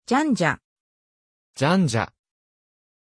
Pronunciation of Janja
pronunciation-janja-ja.mp3